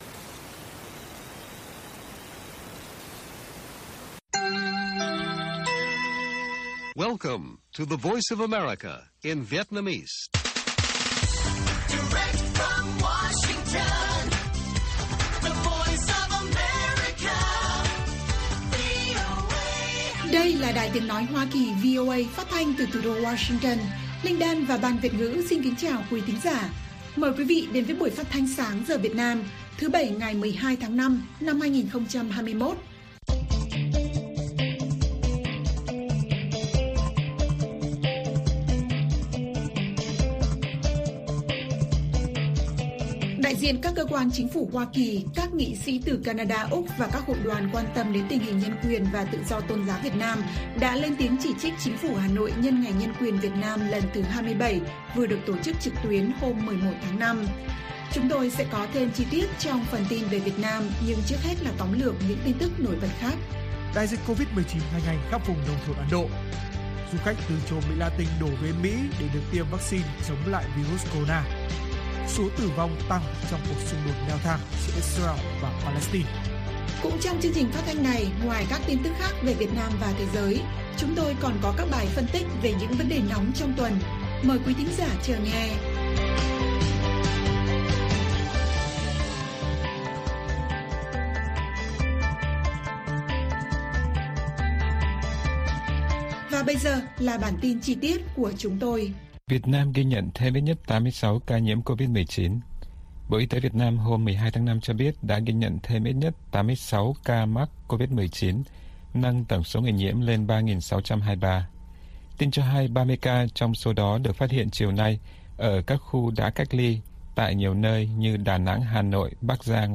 Bản tin VOA ngày 13/5/2021